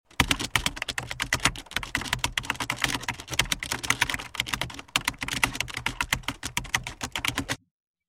Persona usando teclado computadoras: Efectos de sonido tecnología
Este efecto de sonido ha sido grabado para capturar la naturalidad del proceso de una persona escribiendo en un teclado de computadora, proporcionando un sonido claro y distintivo que se integrará perfectamente en tus proyectos.
Tipo: sound_effect
Persona usando reclado computadoras.mp3